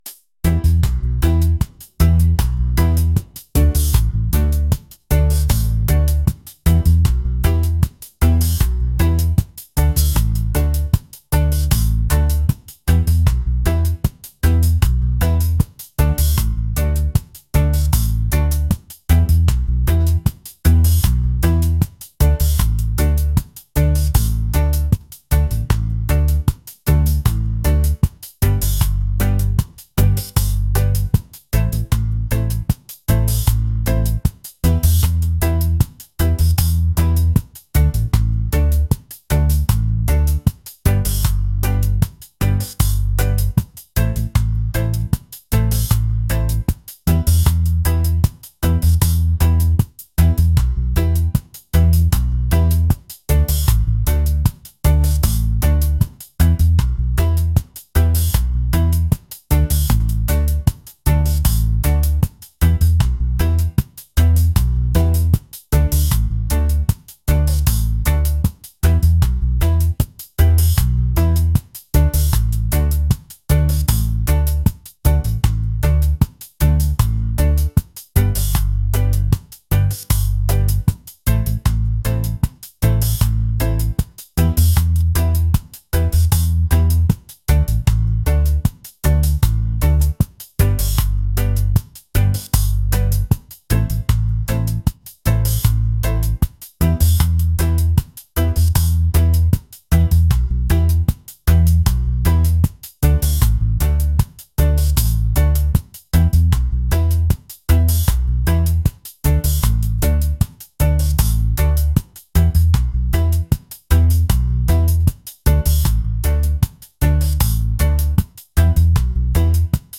laid-back | reggae | romantic